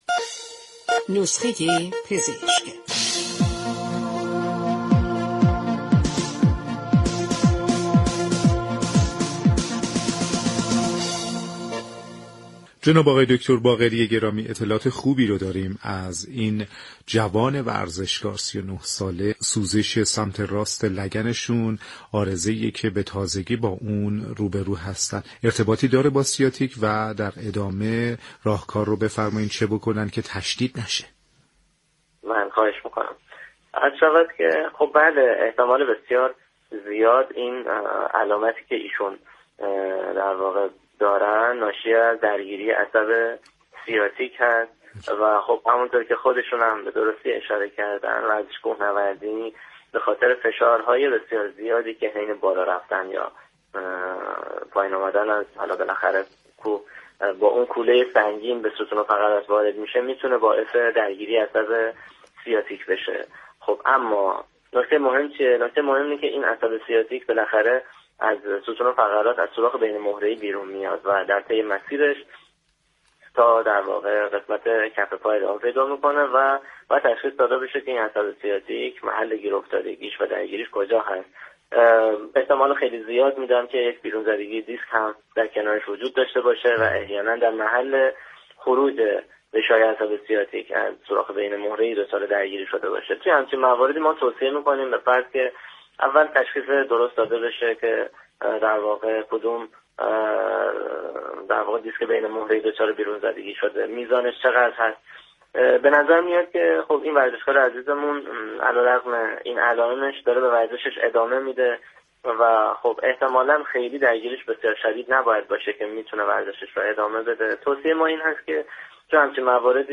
/صوت آموزشی/
در گفت و گو با برنامه نسخه ورزشی رادیو ورزش